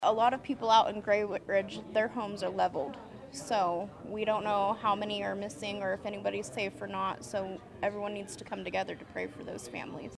A relief center volunteer